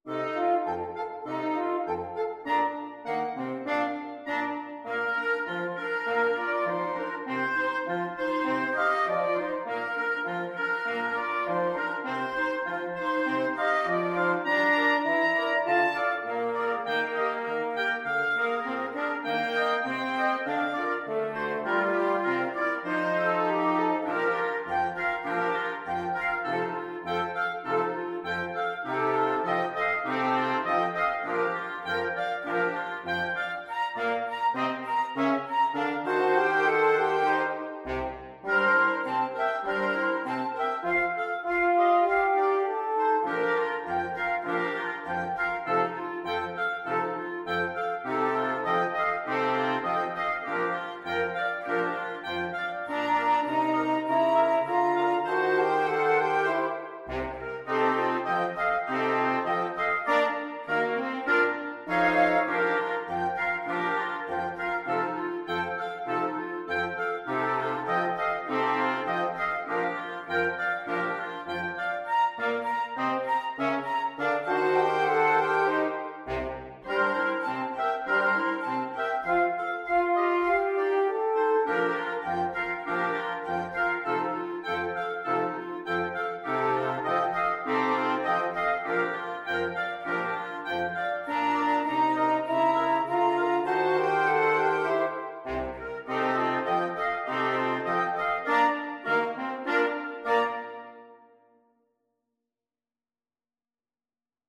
Wind Quintet version
FluteOboeClarinetFrench HornBassoon
2/2 (View more 2/2 Music)
Moderato =c.100